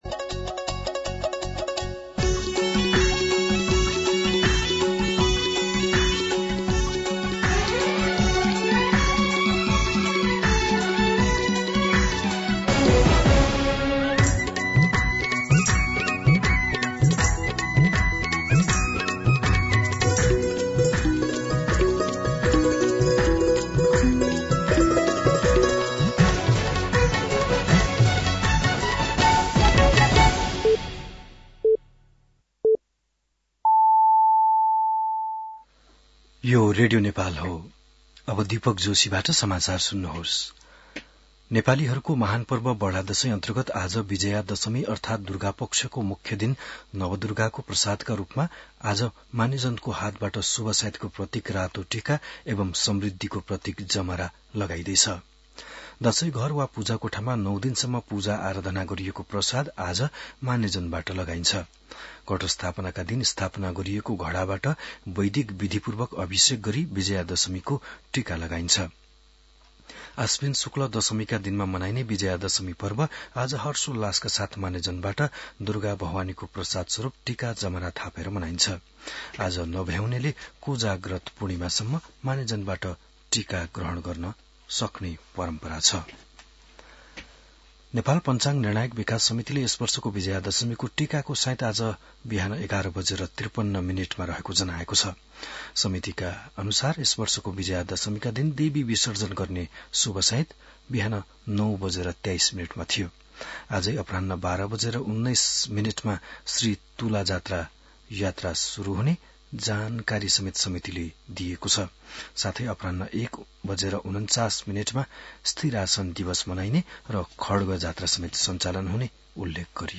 बिहान ११ बजेको नेपाली समाचार : १६ असोज , २०८२